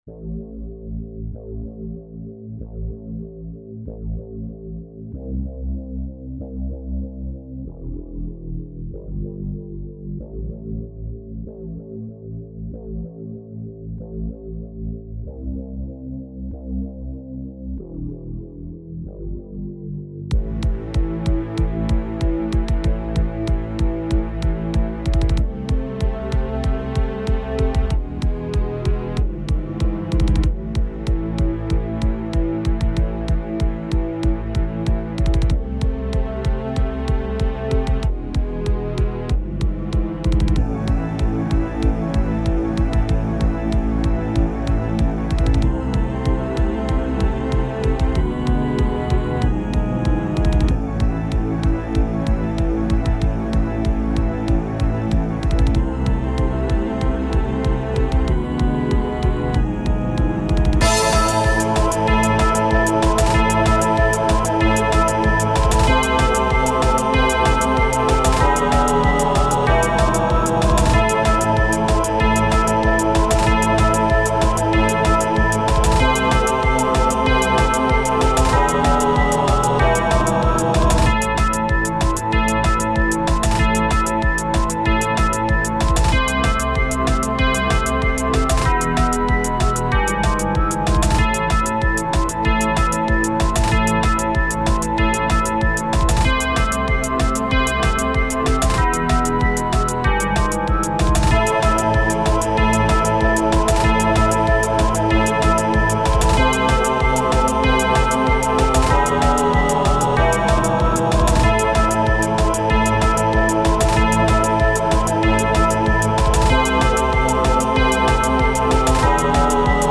chase theme